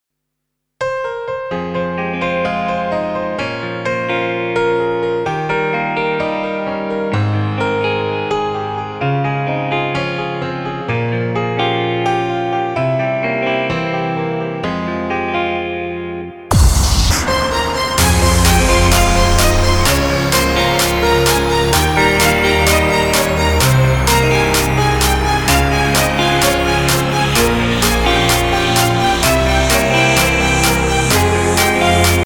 • Качество: 320, Stereo
без слов
красивая мелодия
скрипка
пианино